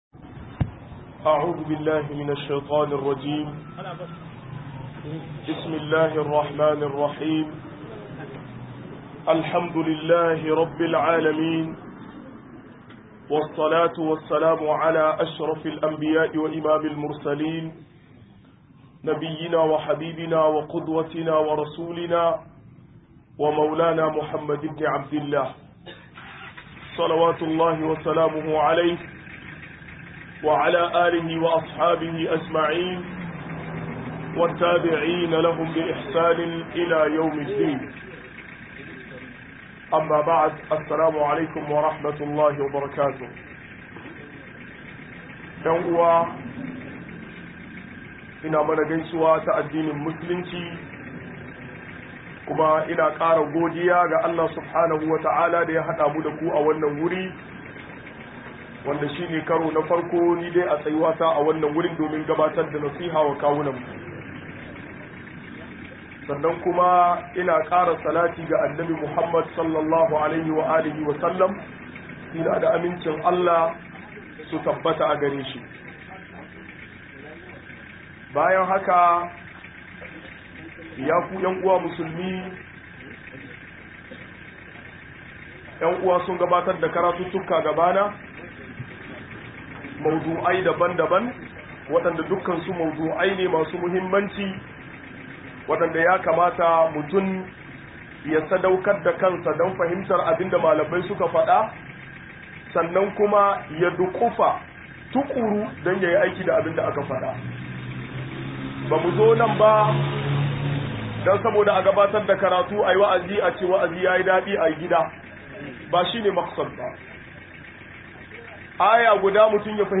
84 - MUHADARA